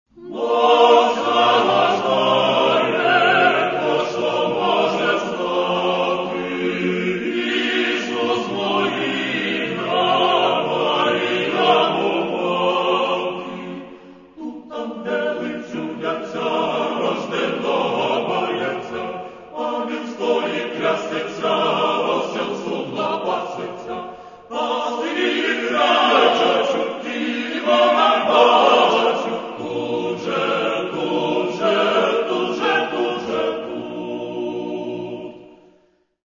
Каталог -> Церковная -> К Рождеству